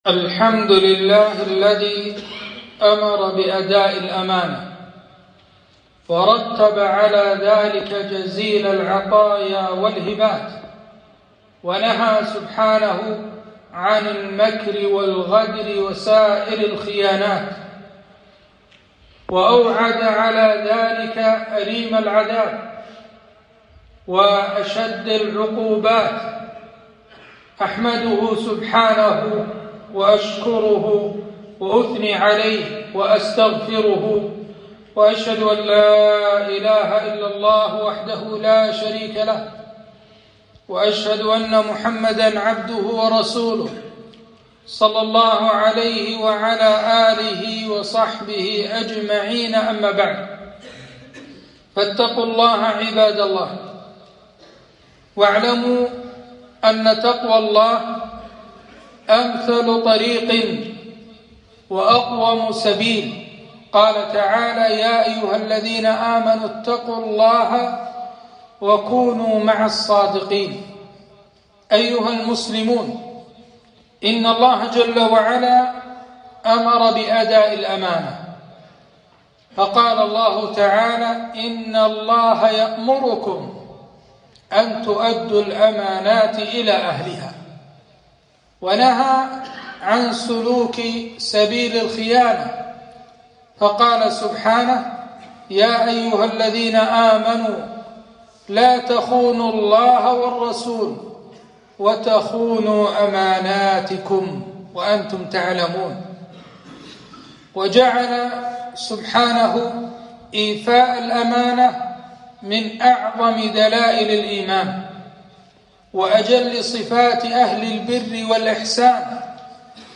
خطبة - الأمانة في الإسلام